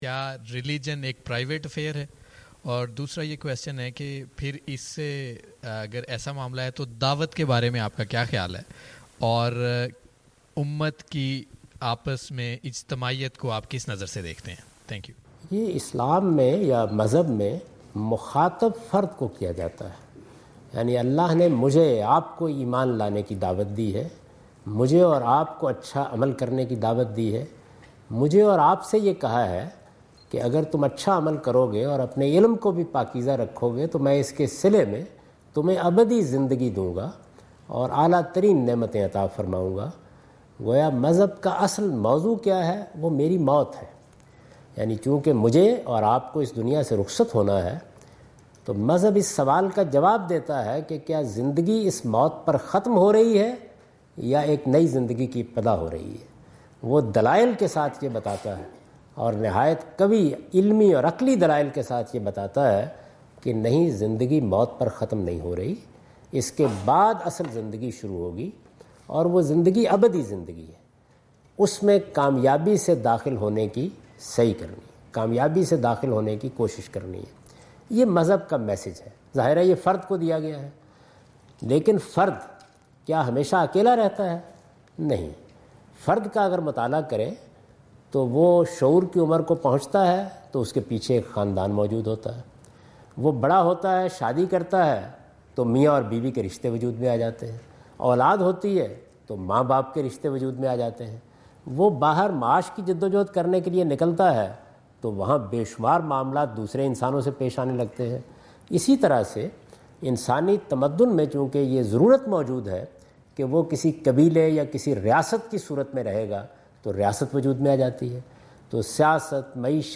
Javed Ahmad Ghamidi answer the question about "is religion a private affair?" in Macquarie Theatre, Macquarie University, Sydney Australia on 04th October 2015.